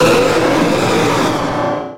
Grito de Mega-Rayquaza.ogg
Grito_de_Mega-Rayquaza.ogg.mp3